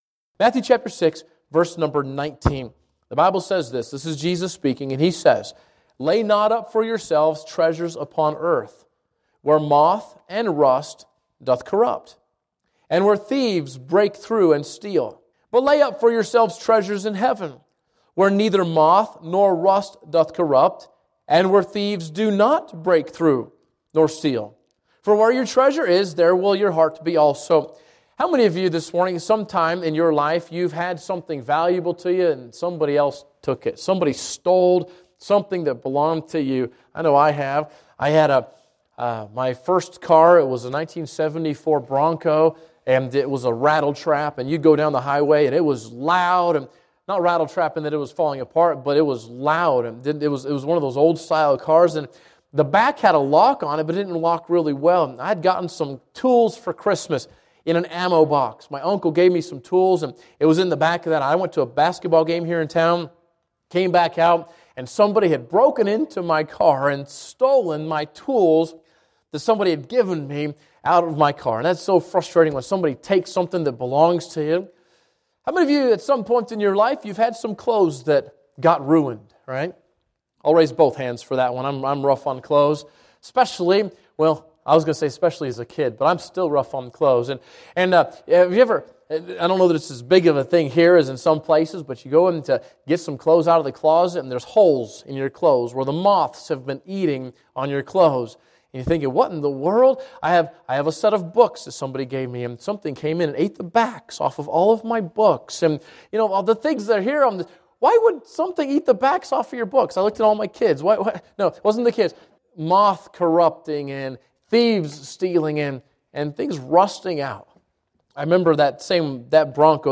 Mission Conference 2018